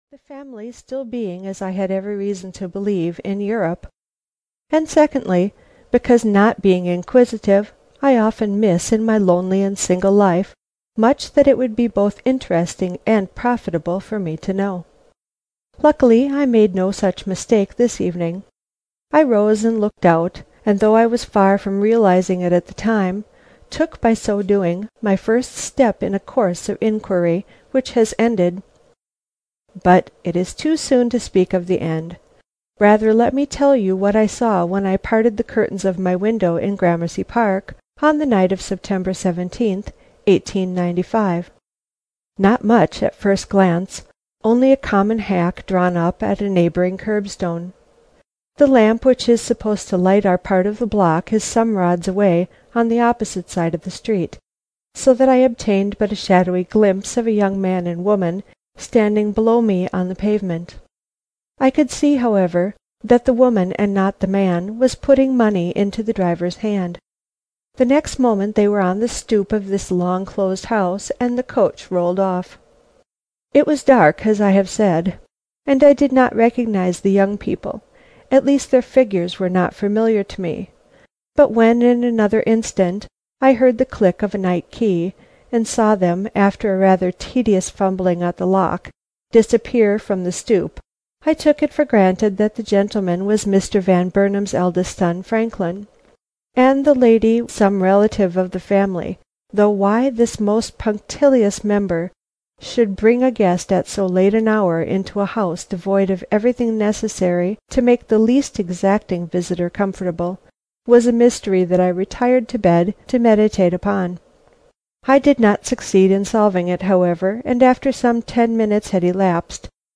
That Affair Next Door (EN) audiokniha
Ukázka z knihy